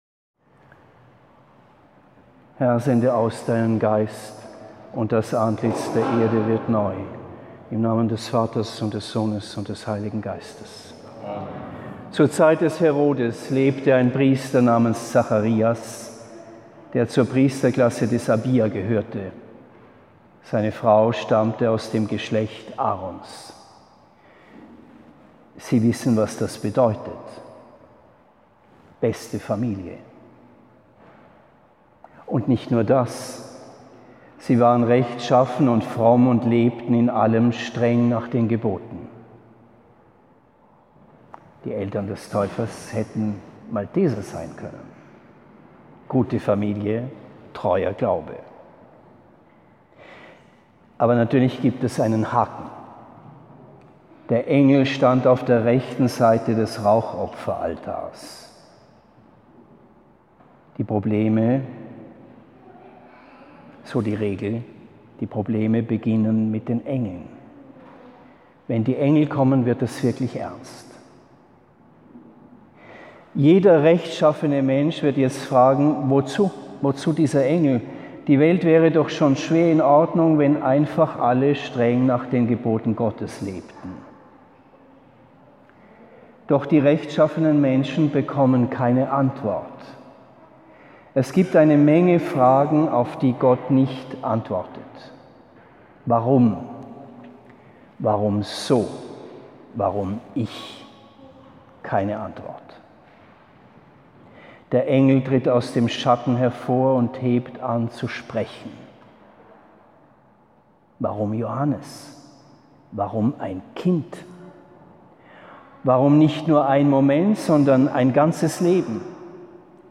Hochfest Johannes des Täufers, Aufnahme neuer Mitglieder in den Orden, Mailberg, 3. Juli 2021